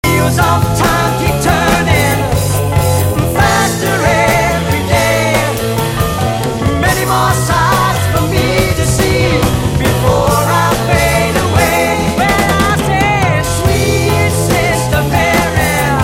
決して曲の邪魔にはなっていないが、かなり手数は多い。
ソウルっぽいCのサビでツーバス連打はしないだろう。